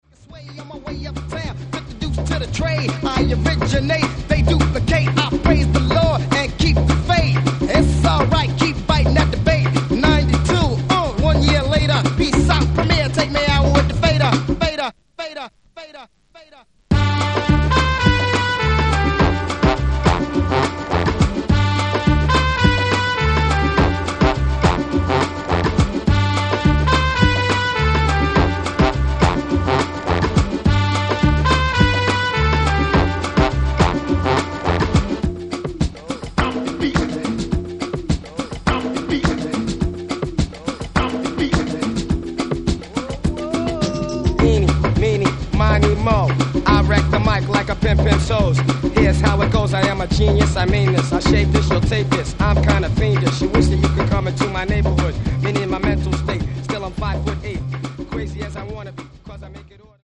Topnotch re-edit